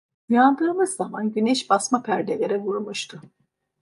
Pronunciato come (IPA) /basˈma/